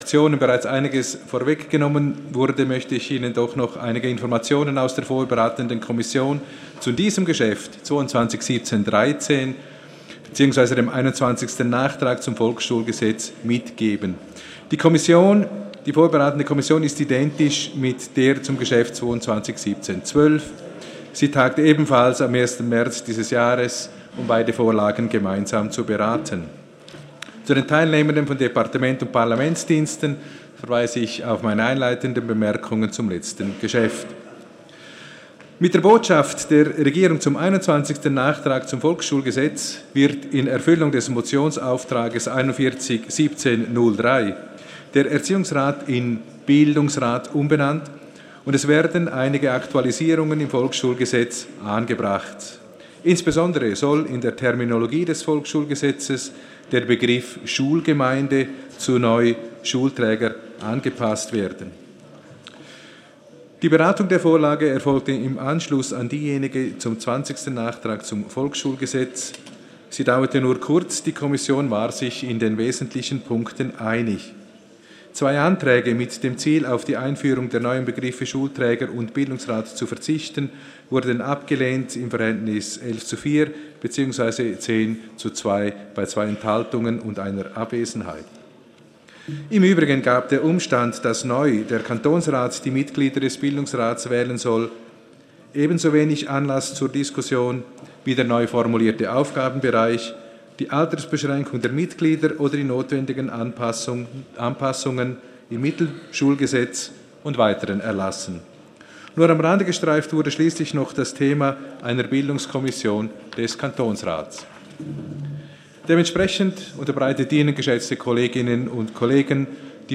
Session des Kantonsrates vom 23. und 24. April 2018